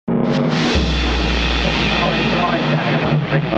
جلوه های صوتی
دانلود صدای رادیو 17 از ساعد نیوز با لینک مستقیم و کیفیت بالا